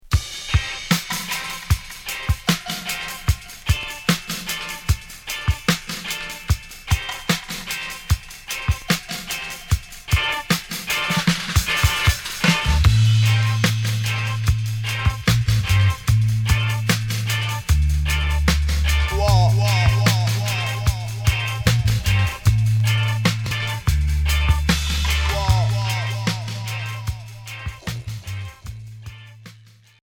Reggae alternatif